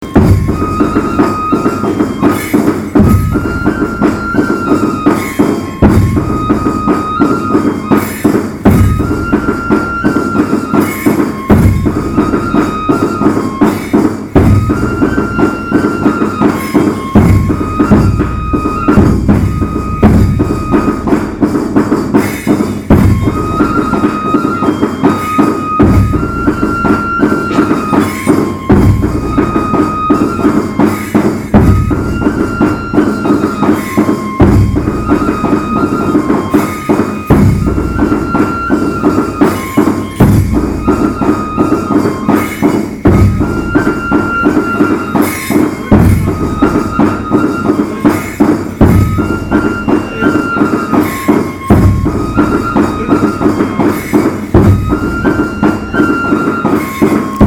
・　旅先で得た音楽や音をアップしました。
◎　お囃子１　　　　◎　お囃子２